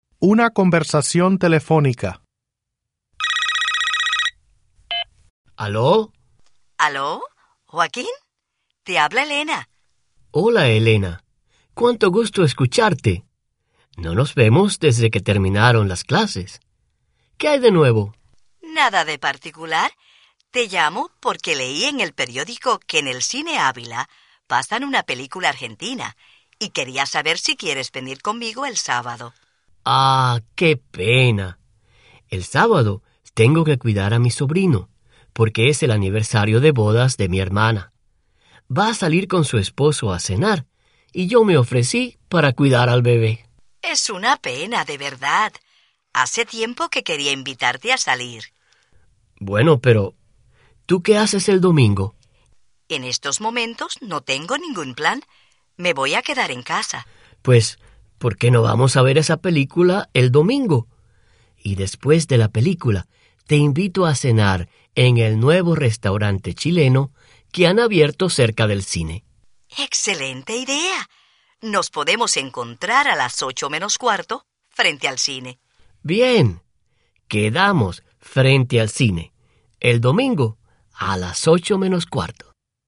C. Conversación telefónica. p.29
Cap07conversaciontelefonicap29.mp3